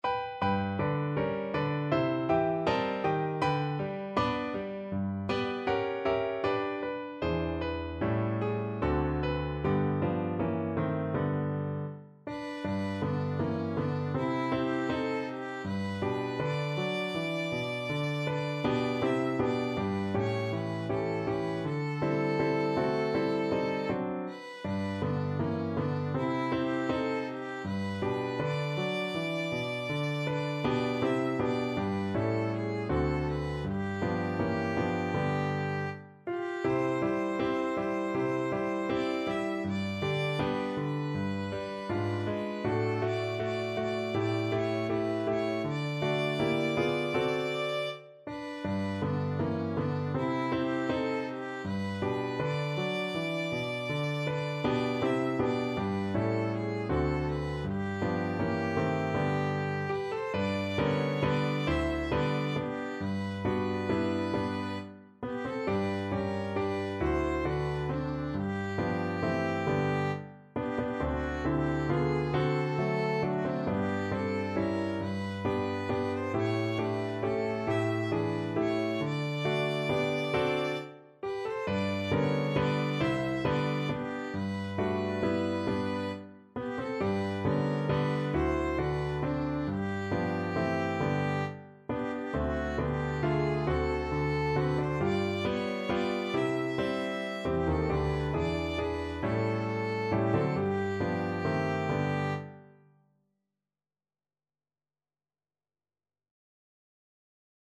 Violin
G major (Sounding Pitch) (View more G major Music for Violin )
4/4 (View more 4/4 Music)
Andante
Pop (View more Pop Violin Music)
shade_apple_tree_VLN.mp3